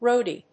/róʊdi(米国英語), rˈəʊdi(英国英語)/